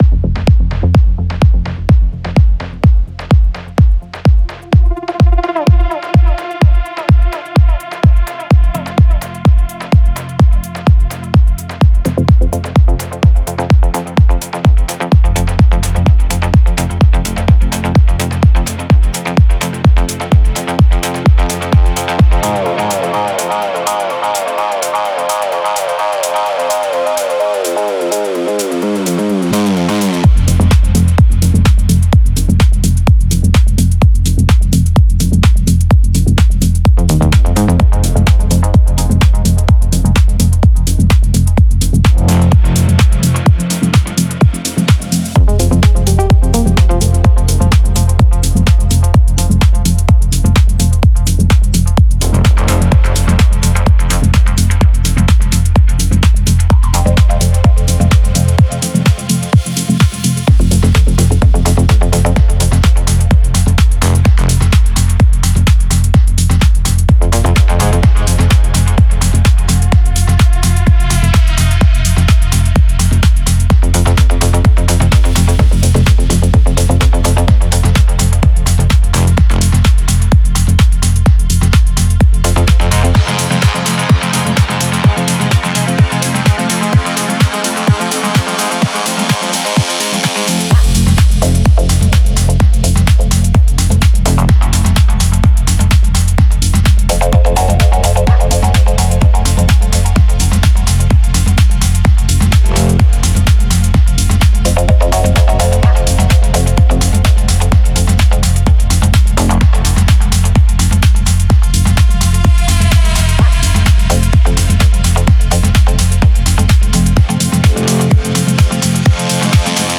Genre Melodic